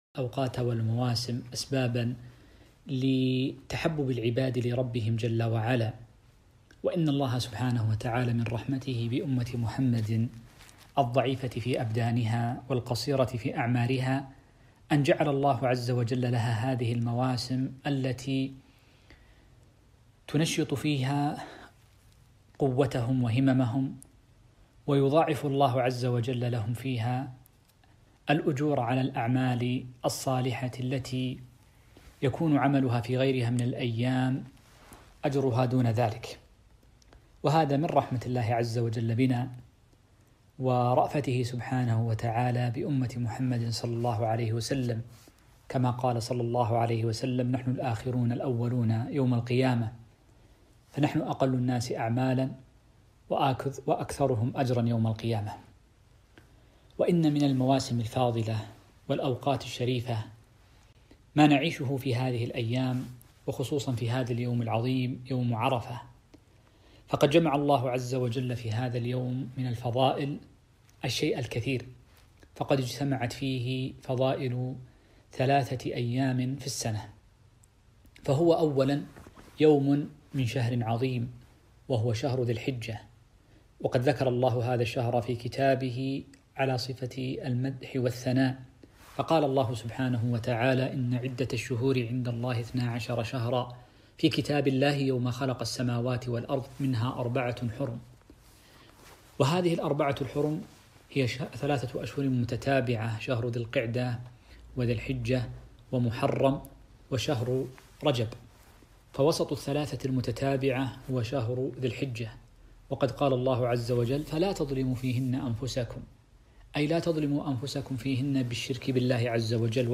كلمة - فضائل وأحكام يوم عرفة